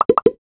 NOTIFICATION_Pop_04_mono.wav